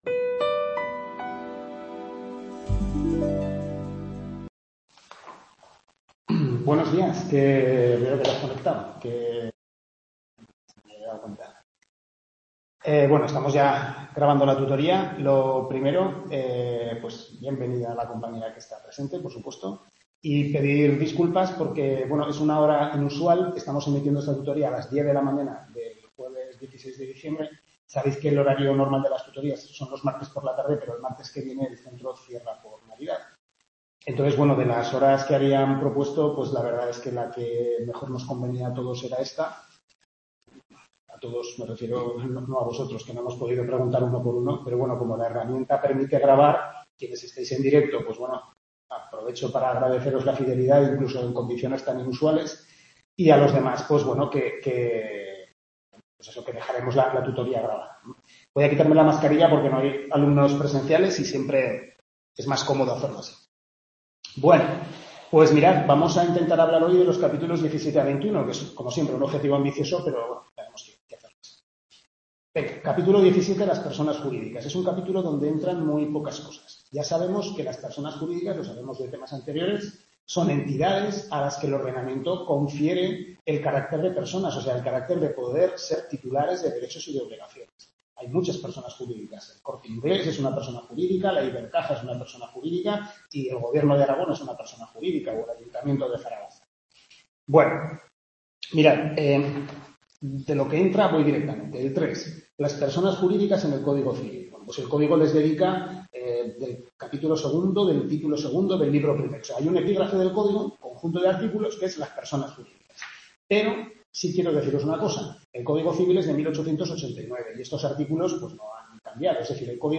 5ª Tutoría Civil I primer cuatrimestre (Parte General), centro Calatayud, capítulos 17-21